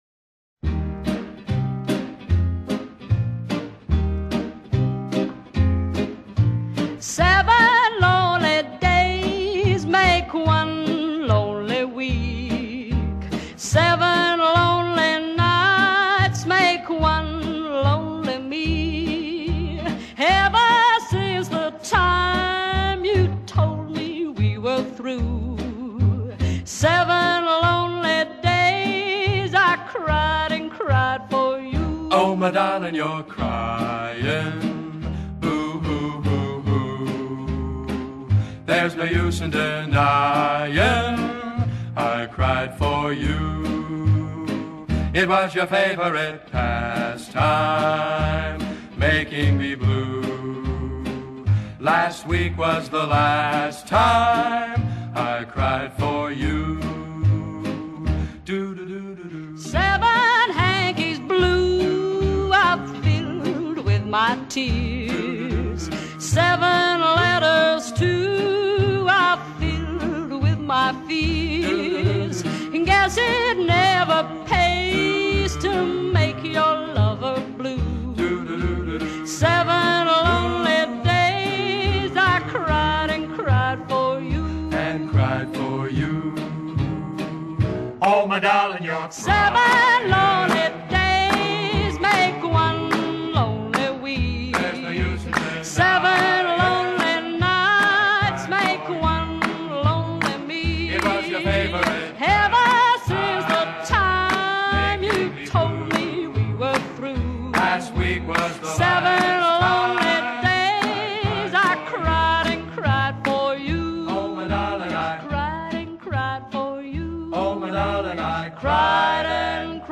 Bluegrass, Folk-Punk, Rock'n'Roll, Oldies | Publisher: n/a